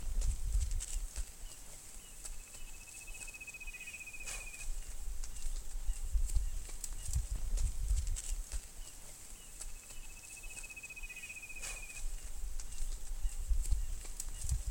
Cistícola Buitrón (Cisticola juncidis)
Localidad o área protegida: Parque Nacional Lago Mburo
Condición: Silvestre
Certeza: Fotografiada, Vocalización Grabada
trilling-cisticola-mburo.mp3